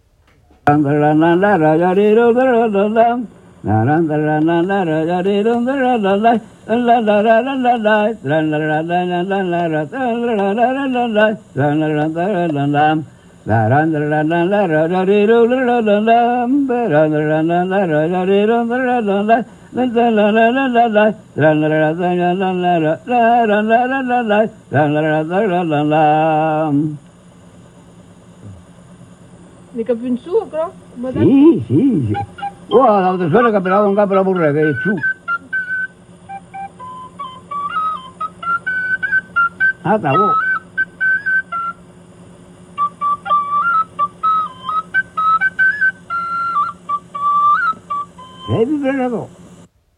Le chant au Tralala
Entendu lors des veillées, le « chant au Tralala » est un chant sans paroles qu’on pratique dans plusieurs régions, entre autres l’Auvergne, le Limousin, les Pyrénées (où il était bien plus courant de l’entendre que d’entendre jouer du hautbois).
Le Tralala est créé sur des onomatopées, quelques syllabes ou des allitérations et parfois il imitera des instruments.
On remarque, à la suite du Tralala (n°1), l’introduction de l’air au clari.